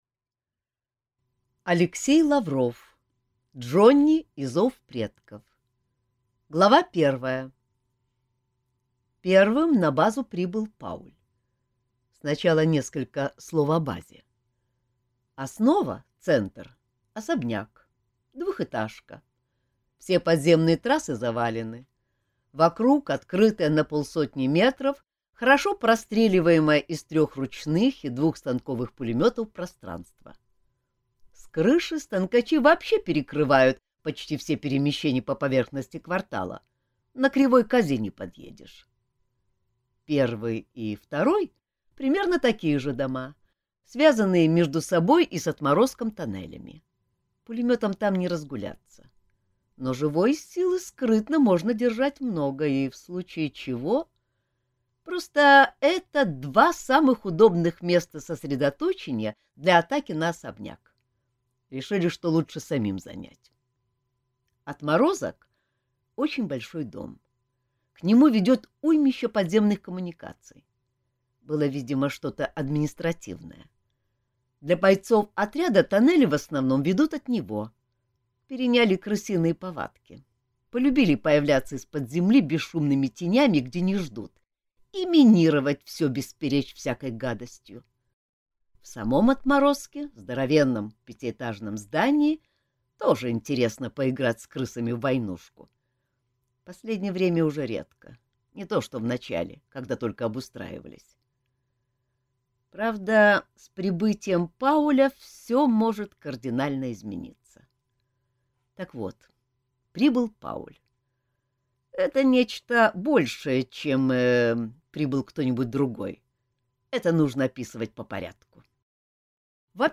Аудиокнига Джонни и «Зов предков» | Библиотека аудиокниг